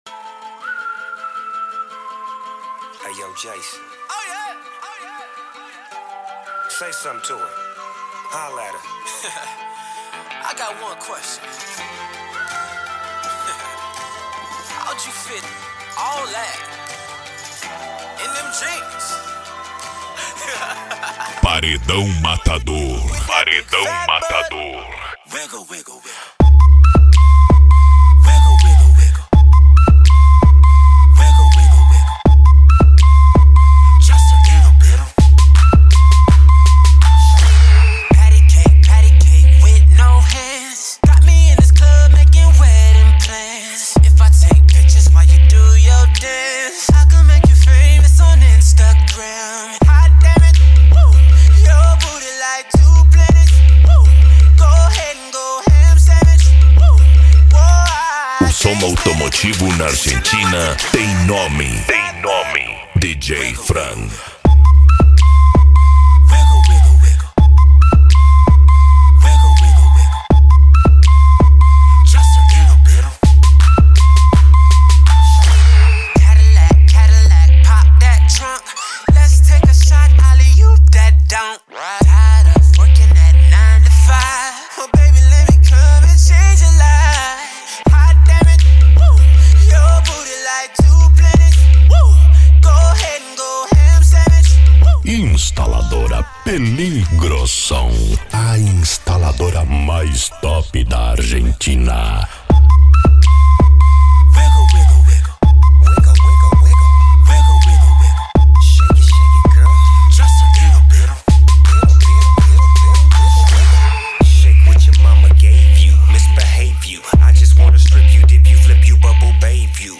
Bass
Mega Funk
Psy Trance